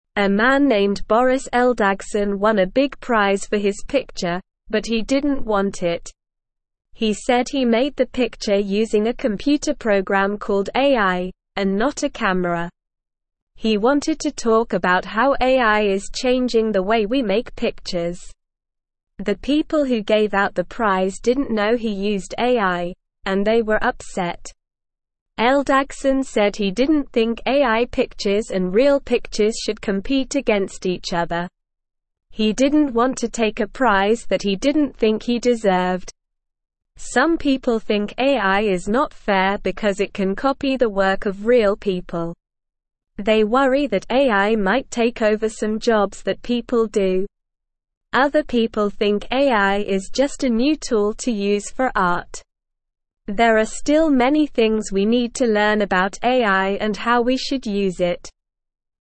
Slow
English-Newsroom-Beginner-SLOW-Reading-Man-Wins-Picture-Prize-Gives-It-Back.mp3